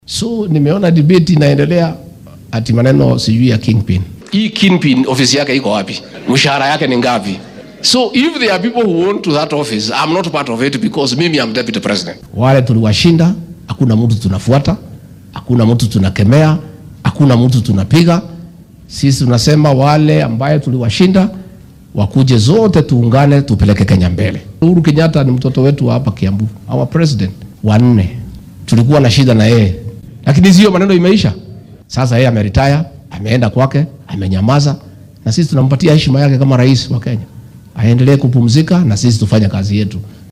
Madaxweyne ku xigeenka dalka Rigathi Gachagua ayaa sheegay in maamulka wadanka hoggaamiyo ee Kenya Kwanza aynan wax dhibaato ah kala dhexeyn ama uunan ka aargoosanayn madaxweynihii hore Uhuru Kenyatta. Waxaa uu sidoo kale beeniyay inuu ku howlan yahay loollan uu ku doonaya in sida uu hadalka u dhigay uu noqdo boqorka arrimaha siyaasadeed ee bartamaha dalka looga dambeeya. Xilli uu ku sugnaa ismaamulka Kiambu, ayuu Rigathi Gachagua sheegay in dastuurka dalka uunan qabin nidaam boqortooyo sidaasi awgeedna doodda arrintan la xiriirta ay tahay mid waxba kama jiraan ah.